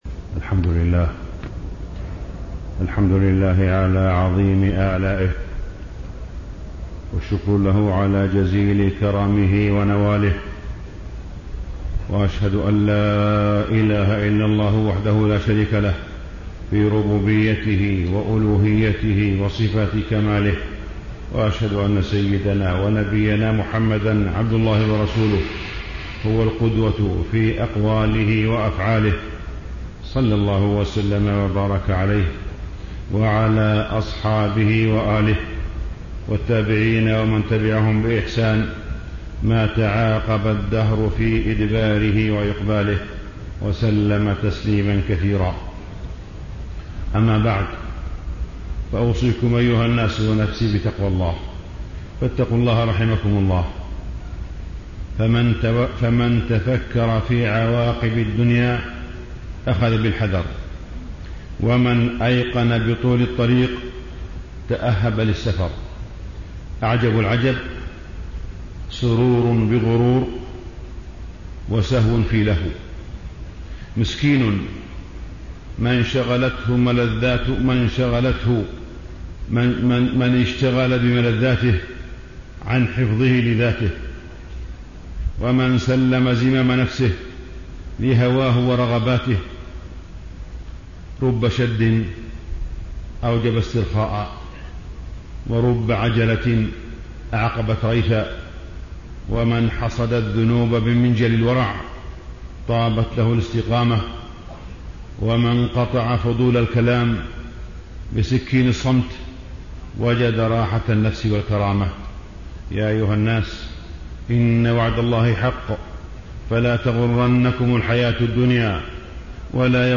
تاريخ النشر ١٢ محرم ١٤٣٥ هـ المكان: المسجد الحرام الشيخ: معالي الشيخ أ.د. صالح بن عبدالله بن حميد معالي الشيخ أ.د. صالح بن عبدالله بن حميد أهمية الأمن والاستقرار